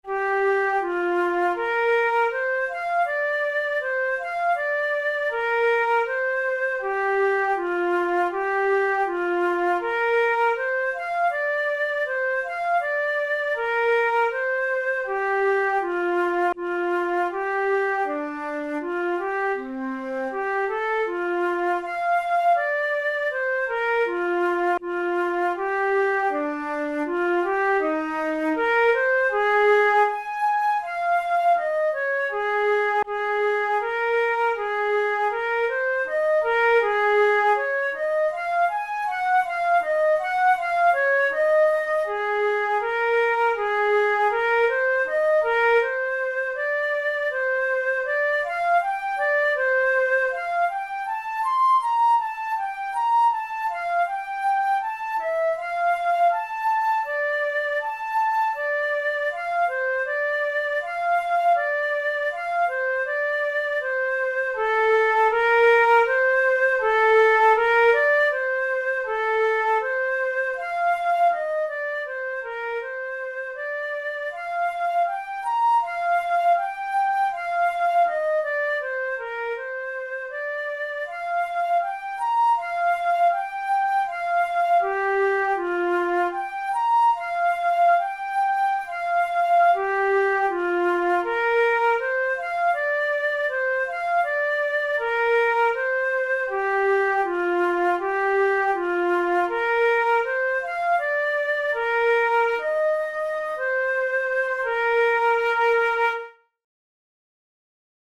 The melody and rhythm resemble Russian folk songs: the piece has simple, strong rhythms in asymmetrical meter.
Categories: Romantic Difficulty: intermediate